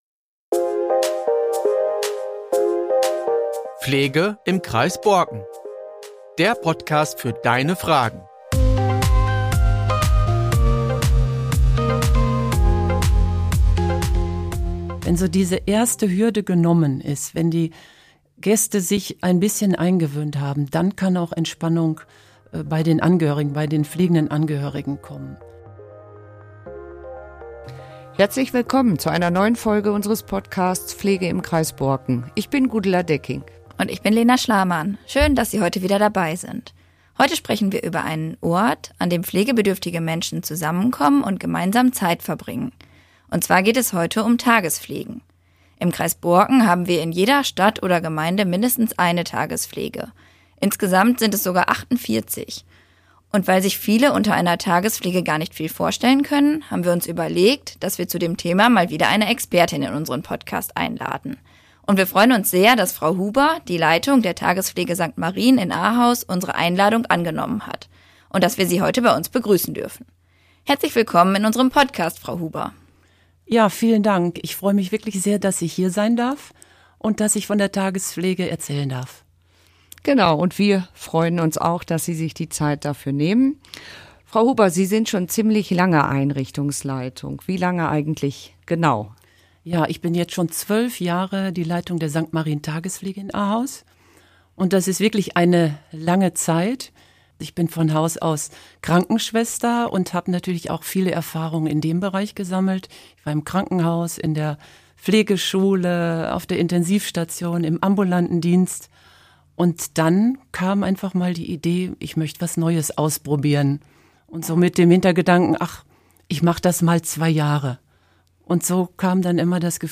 Ein Gespräch